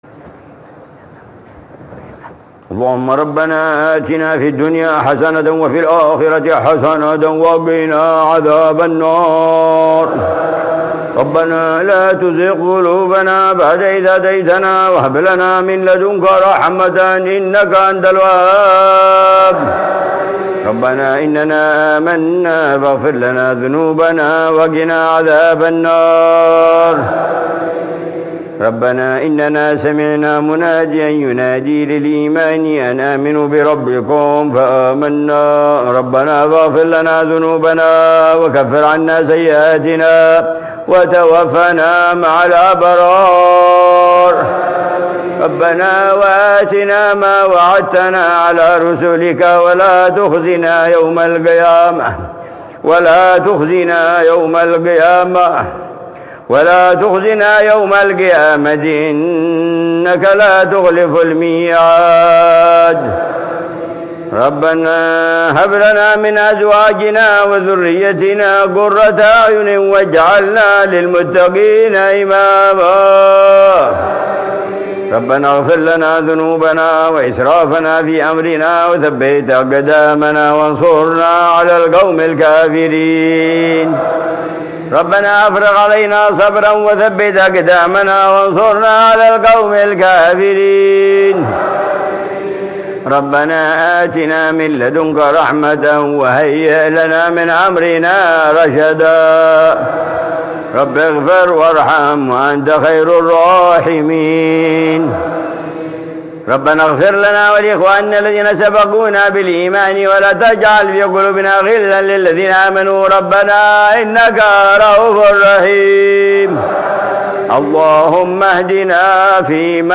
دعاء العلامة الحبيب عمر بن حفيظ في قنوت الوتر، ليلة الجمعة 21 رمضان 1446هـ